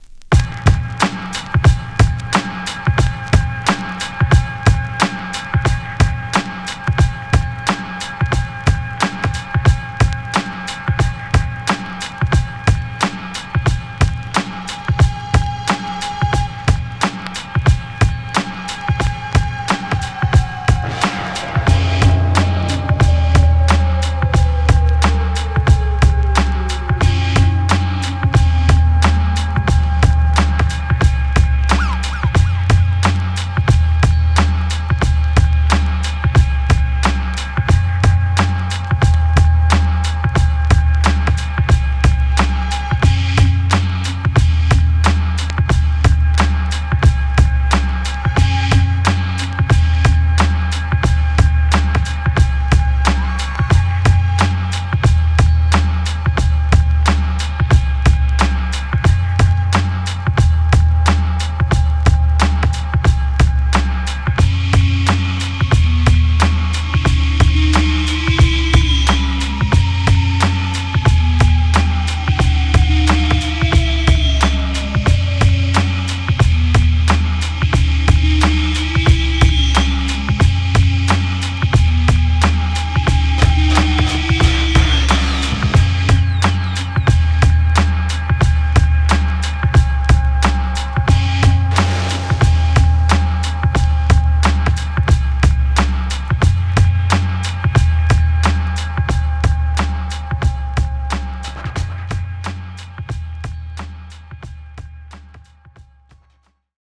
「ABSTRACT,TRIP HOP」